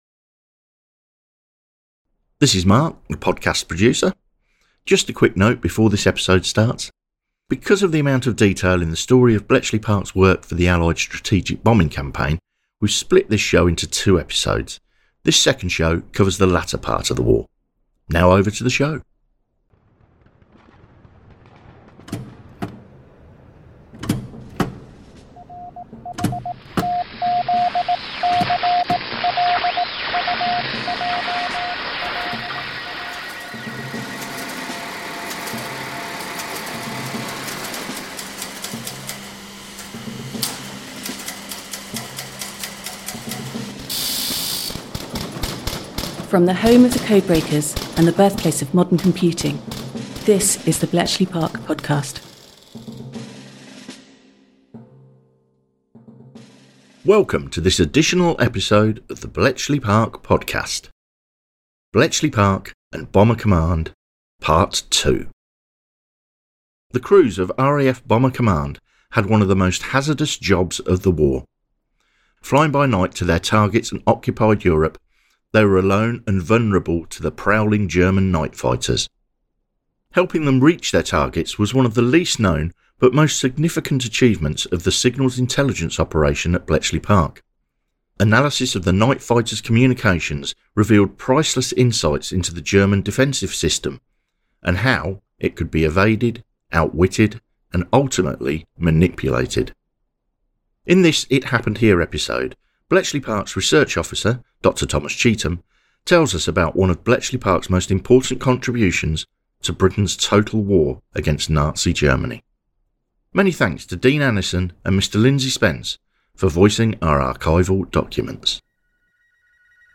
voicing our archival documents